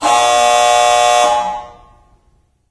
Звук протяжный сигнал.